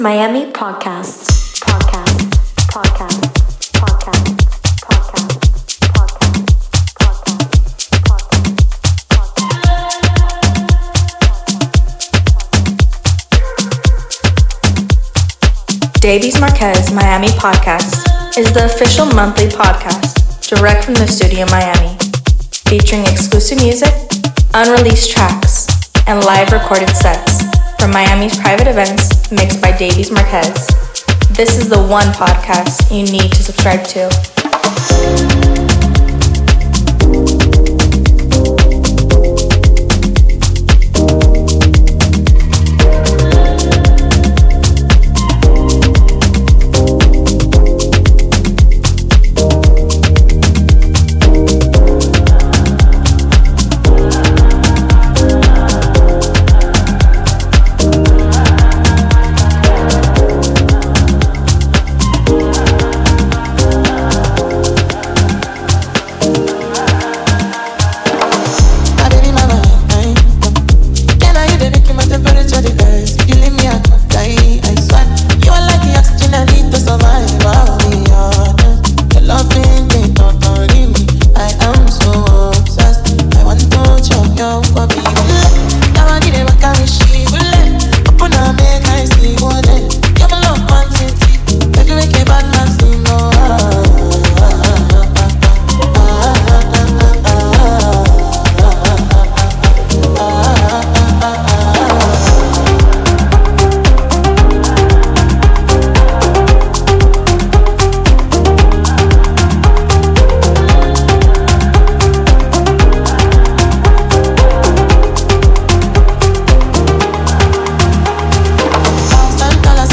Set Recorded at Private Event July 2024
Deep House
tech house